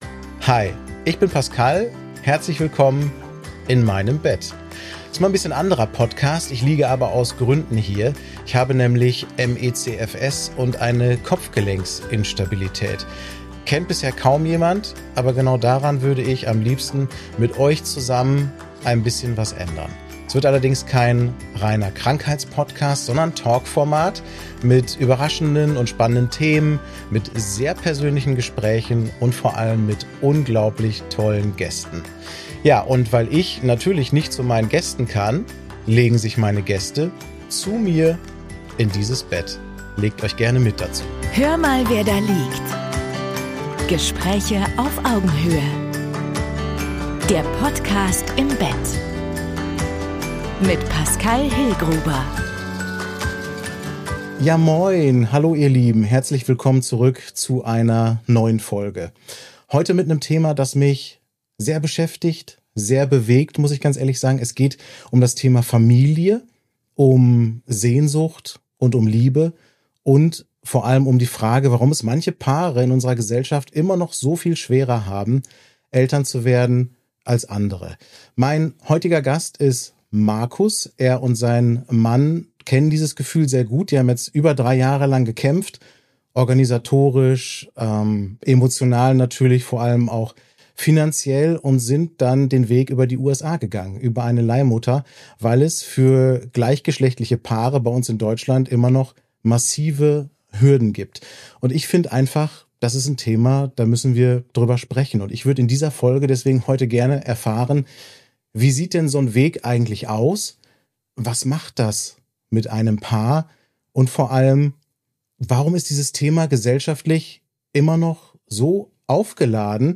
Ein Gespräch über Elternschaft, Verantwortung, Kritik – und über das, worum es am Ende vielleicht wirklich geht: bedingungslose Liebe.